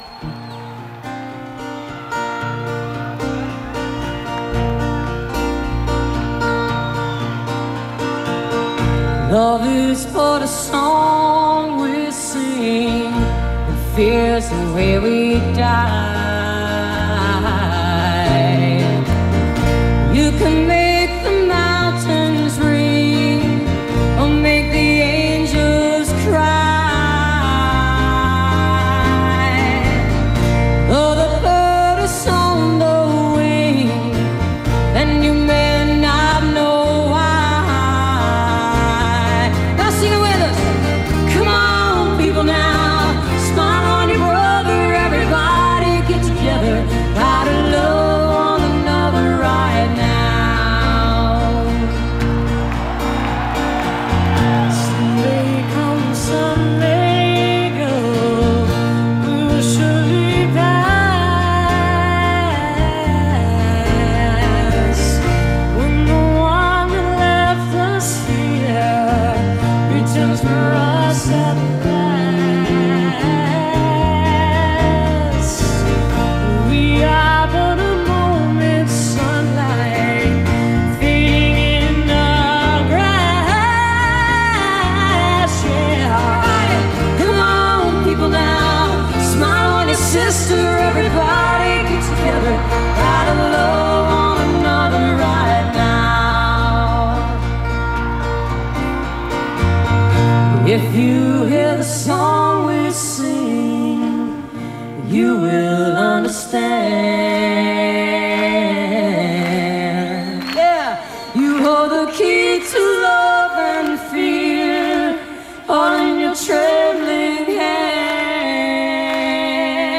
1993-08-22: autzen stadium - eugene, oregon